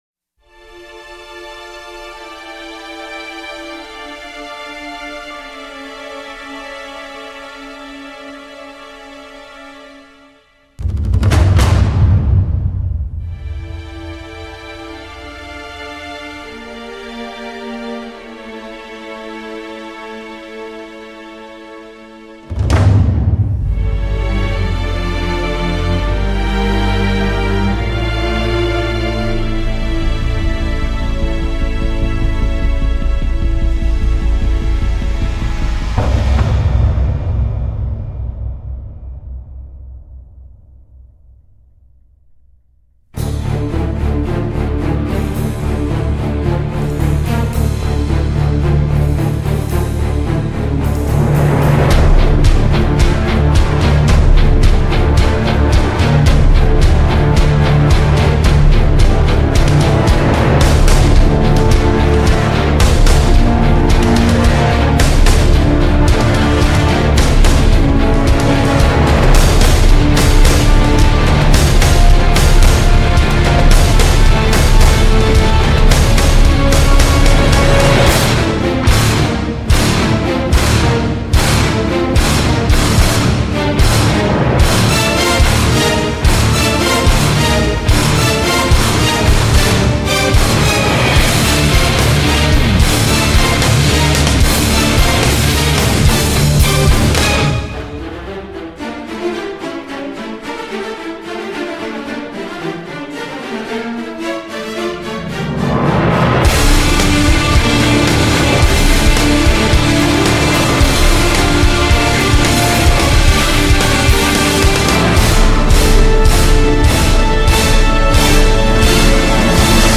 专辑格式：DTS-CD-5.1声道
音乐揉合交响乐及电子乐，加上鼓乐强劲节奏，气势滂薄，亦具史诗式的古典美。